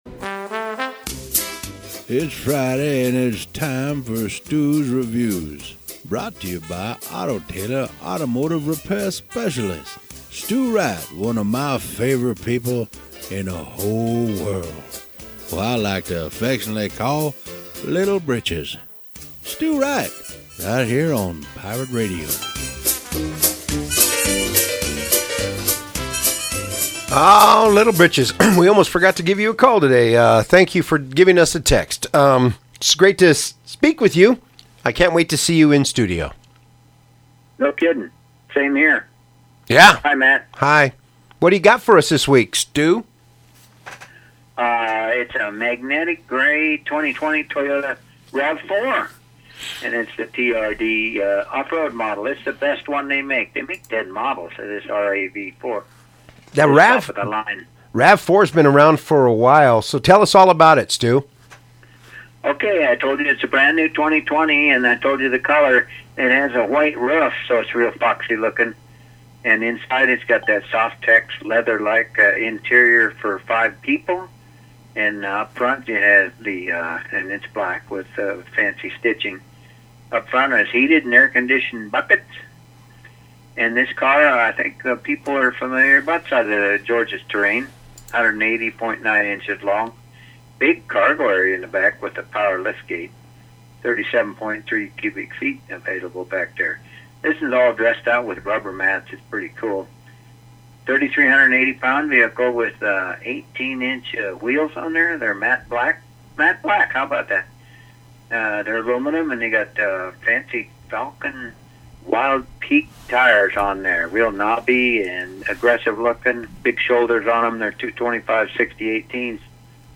Radio review of RAV-4: